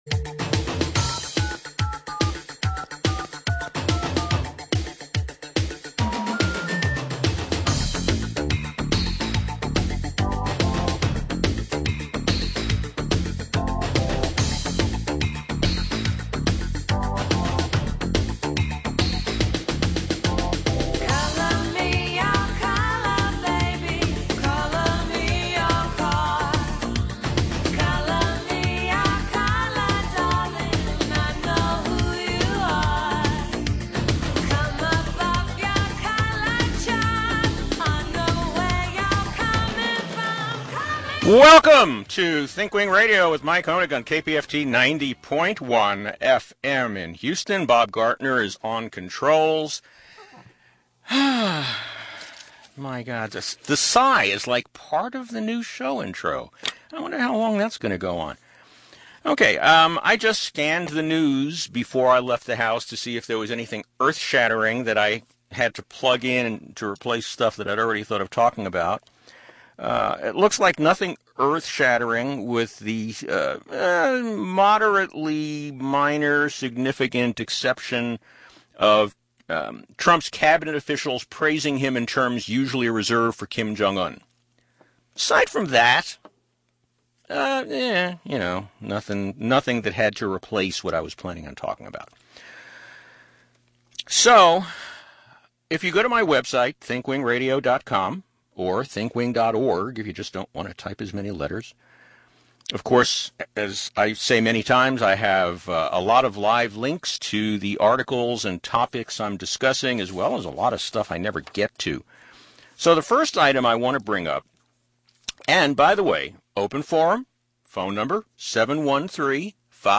We take callers during this show.